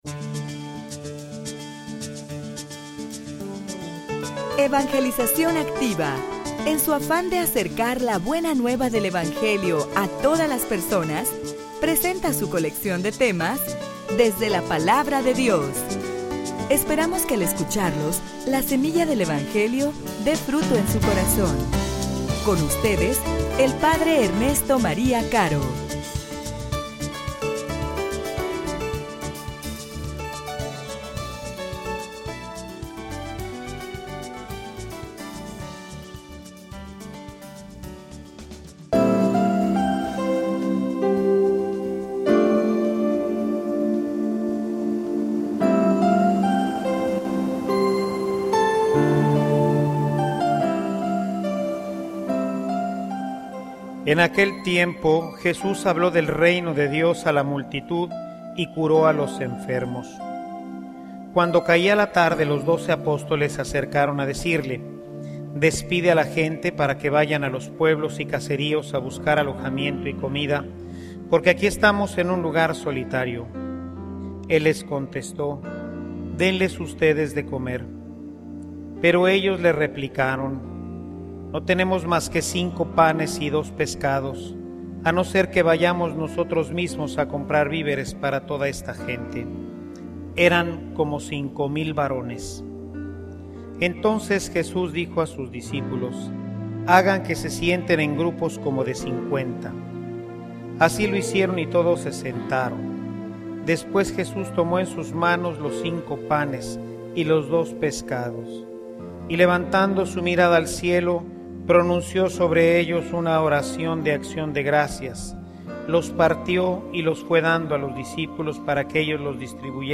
homilia_Alimentate_bien.mp3